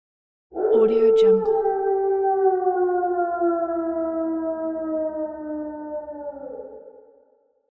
Wolf Téléchargement d'Effet Sonore
Wolf Bouton sonore